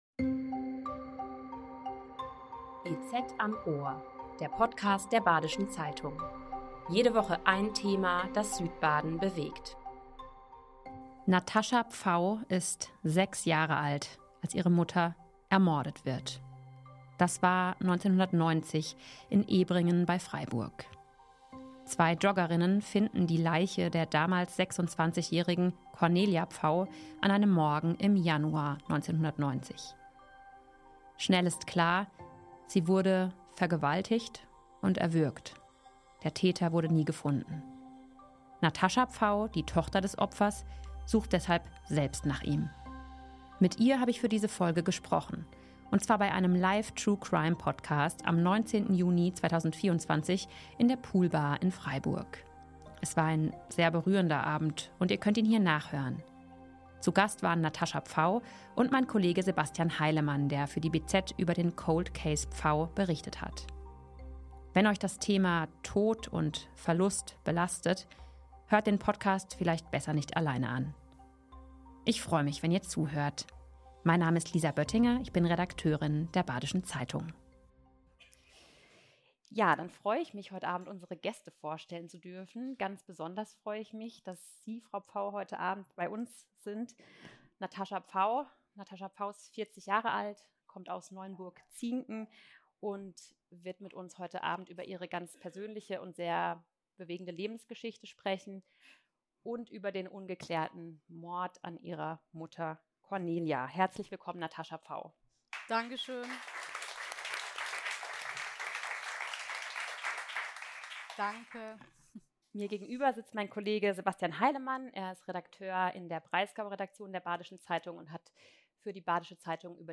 im ersten BZ-Live-Podcast am 19. Juni 2024 in der Poolbar in Freiburg. Außerdem geht es um die Fragen, wie ein Mordfall zu einem Cold Case wird, wie die Polizei heute mit Ungereimtheiten bei ihrer Arbeit von vor 34 Jahren umgeht - und warum die Akten nicht einfach an ein Team in einem anderen Bundesland gehen.